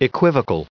added pronounciation and merriam webster audio
294_equivocal.ogg